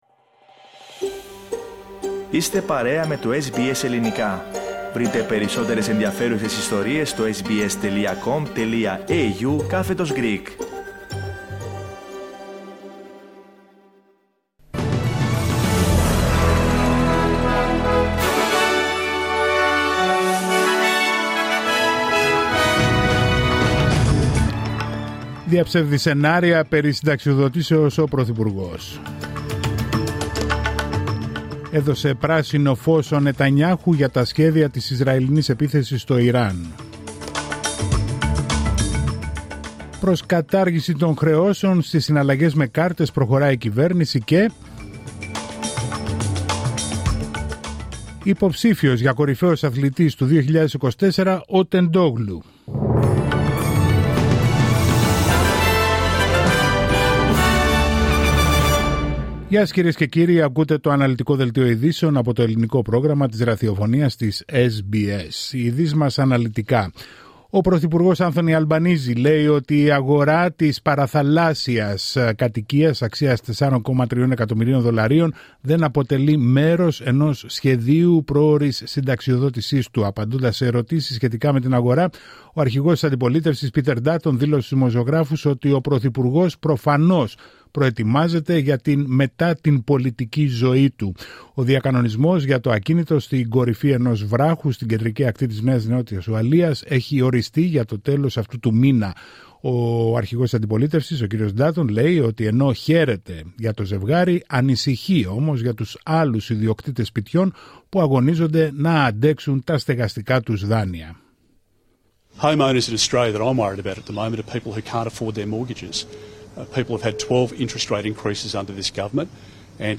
Δελτίο ειδήσεων Τρίτη 15 Οκτωβρίου 2024